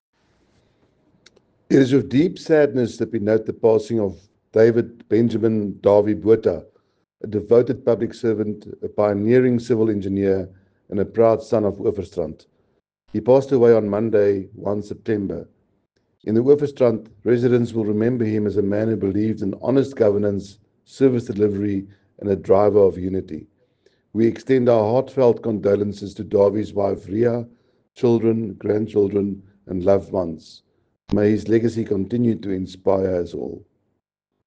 soundbite